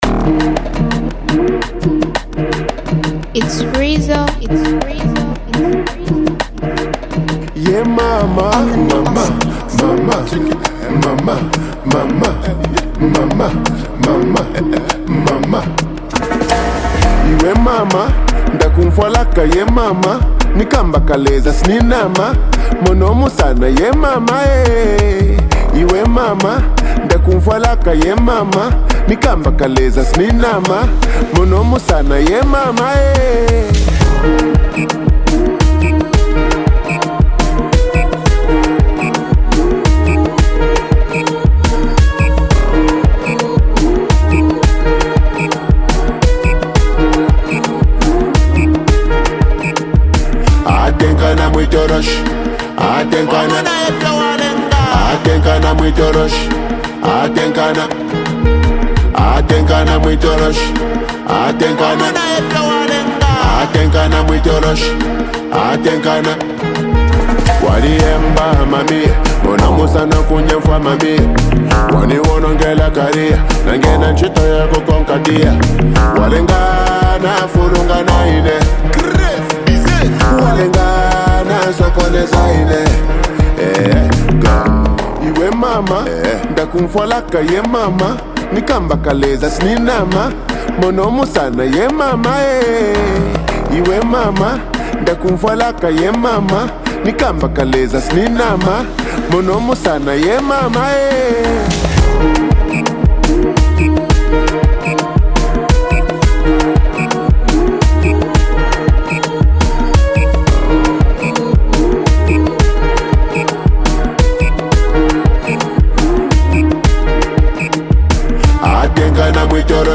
weaving in heartfelt lyrics with catchy melodies.